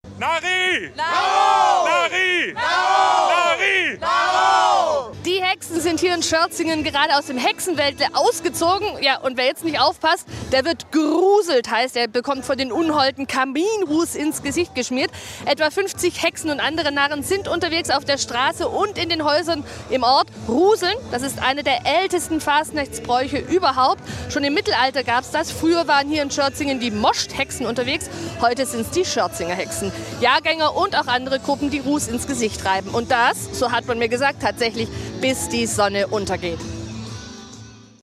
Die Hexen sind los: "Rußeln" in Schörzingen
Die Schörzinger Hexen sind am Schmotzingen Donnerstag traditionell mit Kaminruß unterwegs.